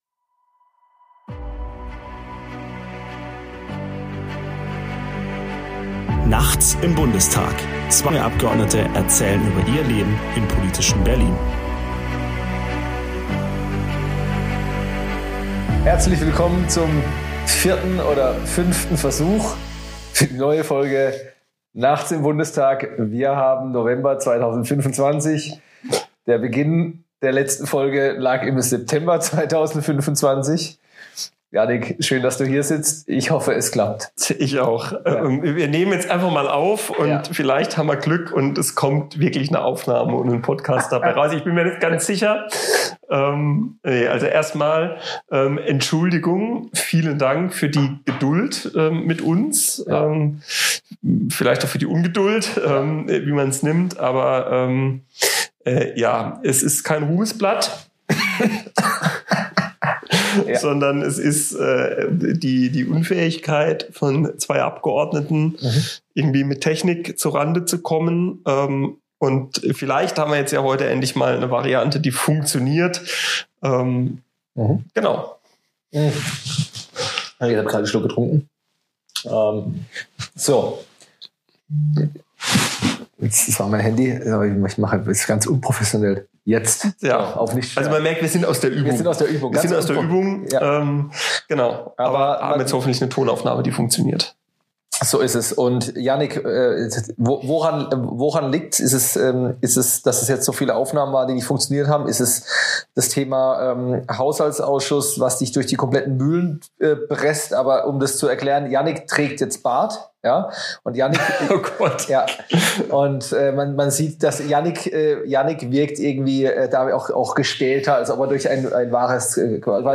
Außerdem diskutieren die beiden über notwendige Sozialreformen und die Herausforderungen, die der politische Herbst mit sich bringt. Wie immer ehrlich, direkt – und diesmal sogar mit funktionierendem Mikro.